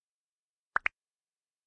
323装填弹药音效03-59小钢炮
修改说明 装填弹药音效03款
323装填弹药音效03.mp3